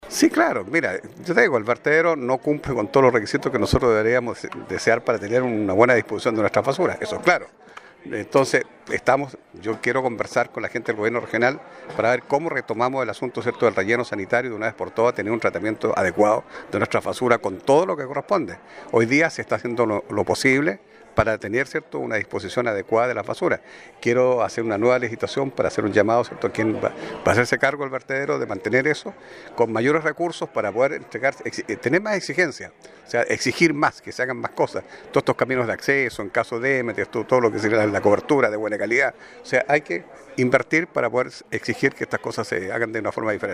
El alcalde de Osorno, Jaime Bertin, informó que la situación está siendo monitoreada y adoptando las medidas para prevenir estas situaciones Frente a las demandas de bomberos sobre la ausencia de guardias en la noche o ausencia de protocolos para la operatividad en estas situaciones, Jaime Bertin indicó que se buscan medidas para mejorar la situación del vertedero.